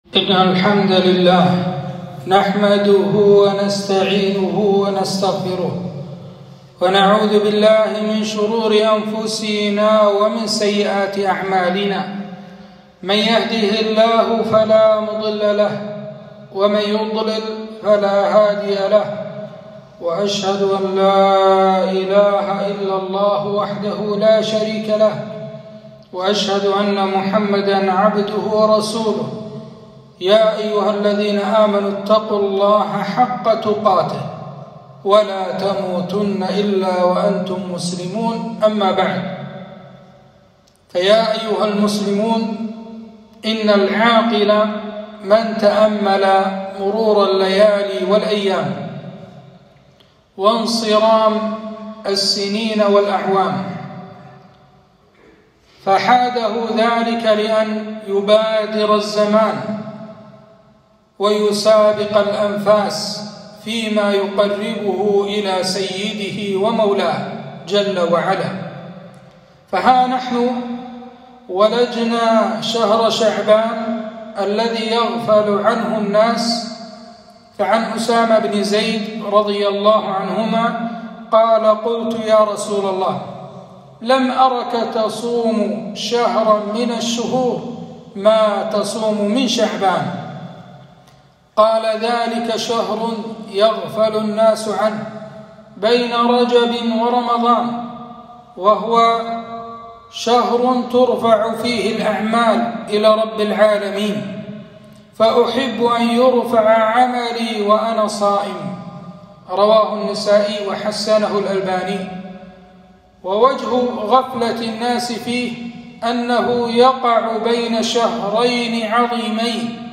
خطبة - شعبان شهر يغفل الناس عنه